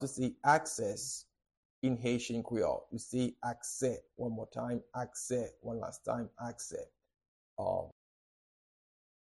how-to-say-Access-in-Haitian-Creole-Akse-pronunciation-by-native-Haitian-teacher.mp3